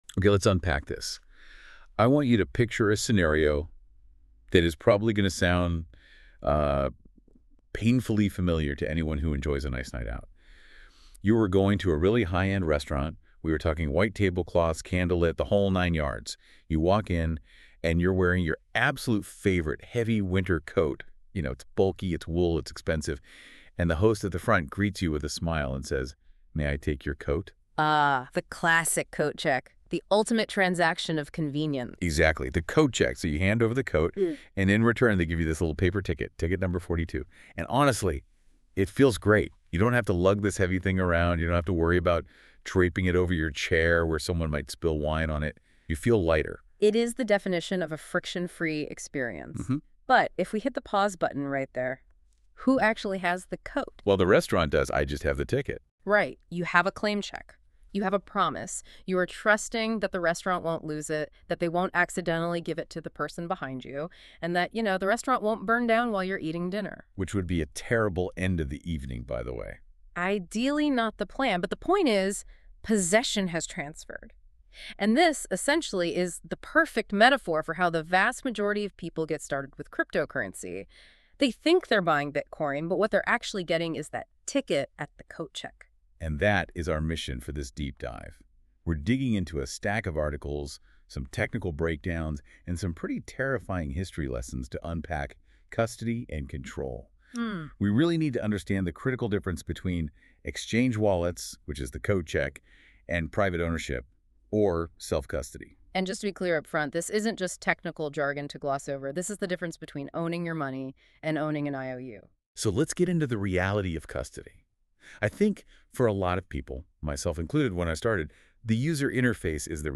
hashtag🎧 Lesson Podcast